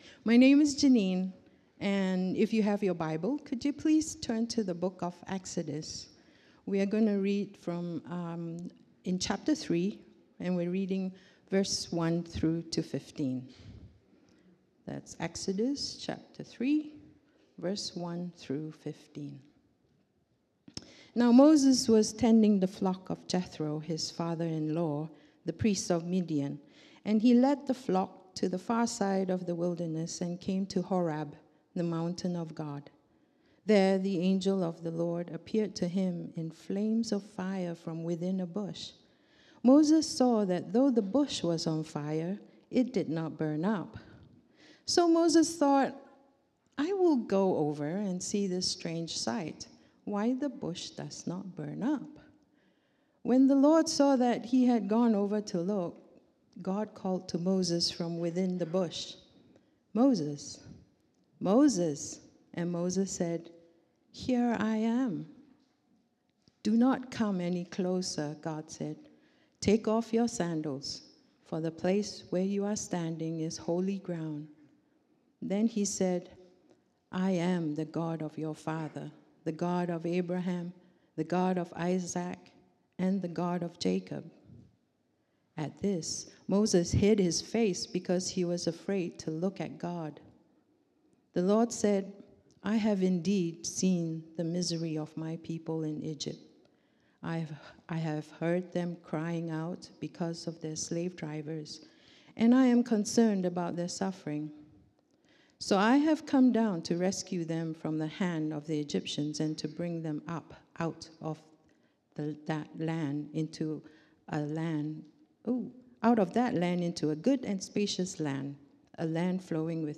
Sermon Series – Hillside Church